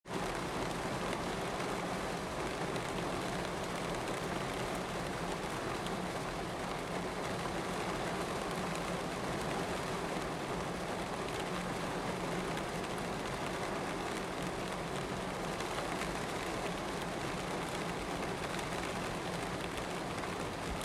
這兩個聲音，給大家猜猜哪個是下雨聲，哪個是炒菜聲？
news033-炒菜聲？下雨聲？4.m4a